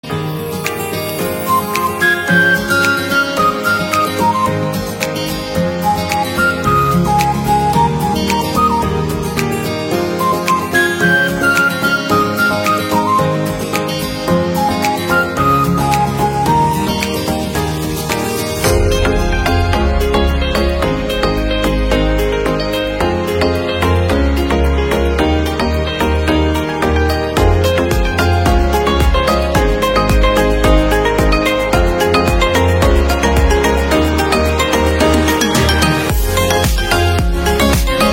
Instrumentalny